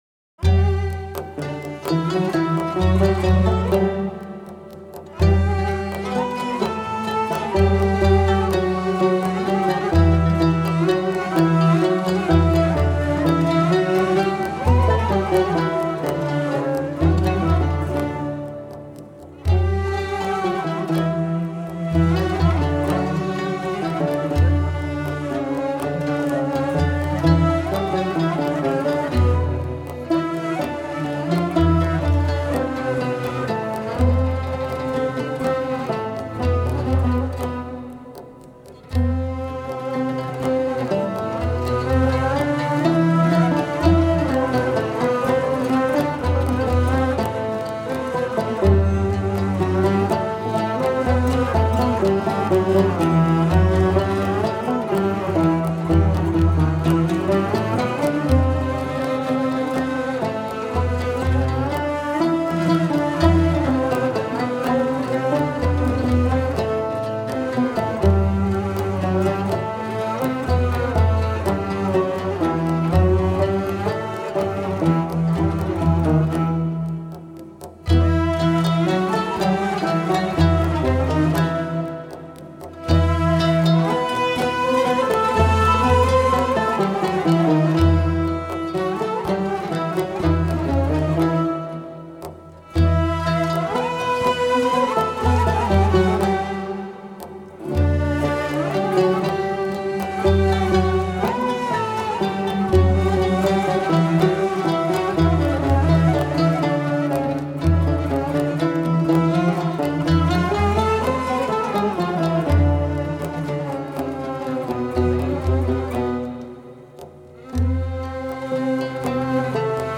Saz Semaisi - Cemil Bey (Tanburi) - Ferahfeza
Eser: Saz Semaisi Bestekâr: Cemil Bey (Tanburi) Güfte Sâhibi: Belirsiz Makam: Ferahfeza Form: S.Eser Usûl: Aksak Semai Güfte: - Kaynak: Sanat Müziği...